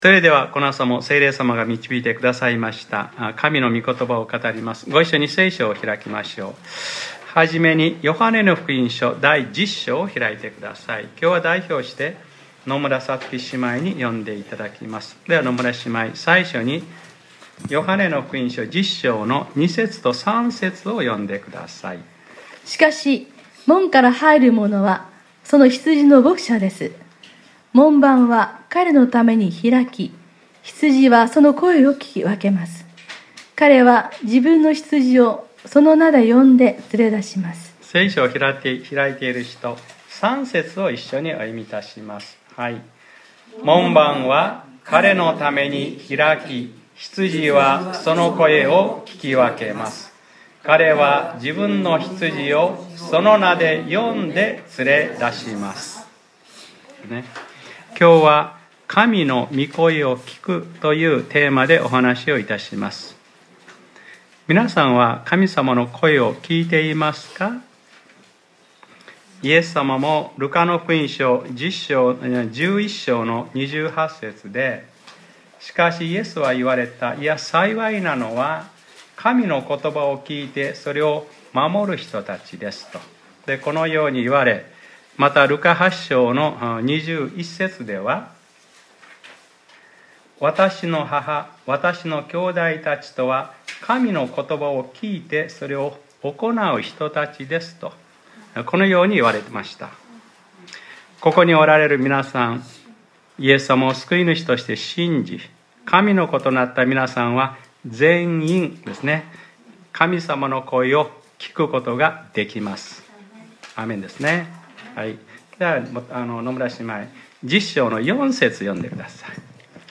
2018年11月04日（日）礼拝説教『神の御声を聴く』